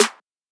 Trap Acoustic Snare G# Key 38.wav
Royality free snare drum sample tuned to the G# note. Loudest frequency: 3755Hz
trap-acoustic-snare-g-sharp-key-38-uCg.mp3